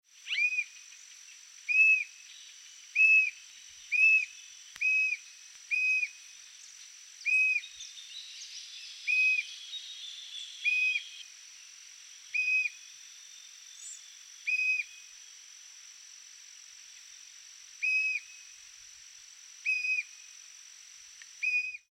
Rufous-capped Antshrike (Thamnophilus ruficapillus)
Sex: Male
Life Stage: Adult
Location or protected area: Delta del Paraná
Condition: Wild
Certainty: Observed, Recorded vocal
choca-corona-rojiza.mp3